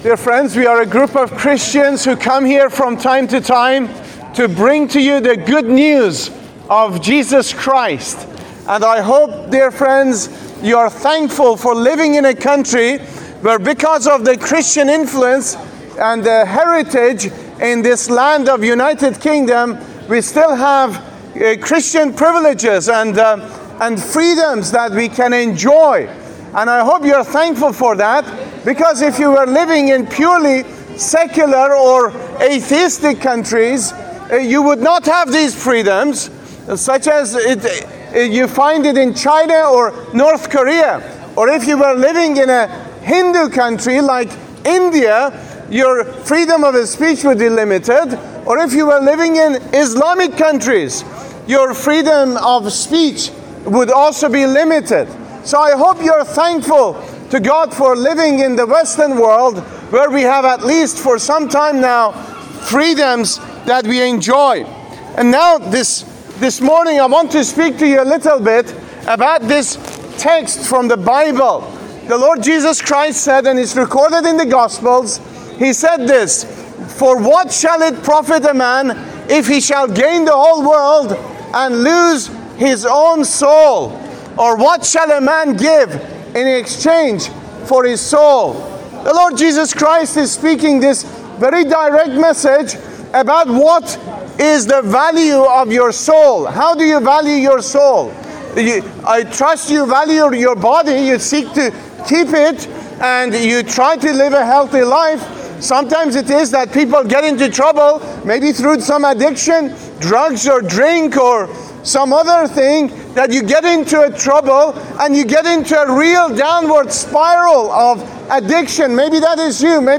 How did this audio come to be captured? Gloucester Open Air Preaching